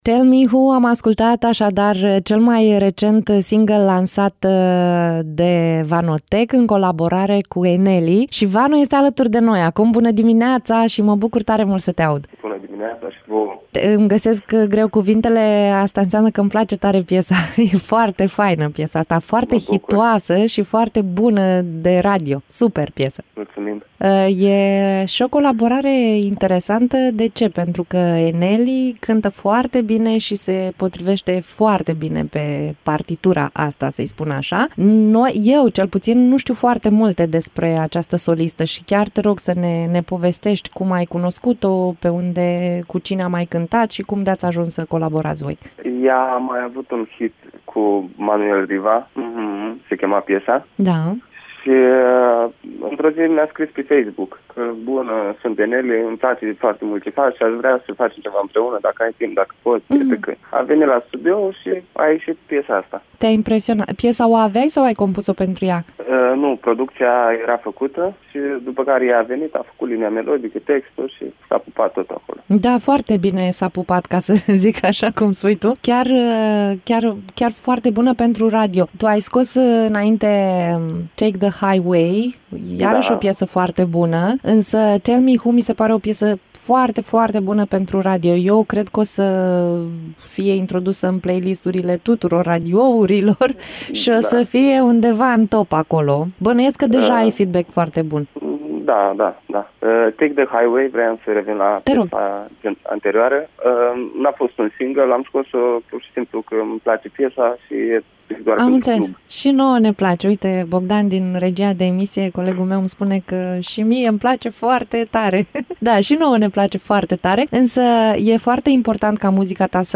Varianta audio a interviului:
interviu-vanotek.mp3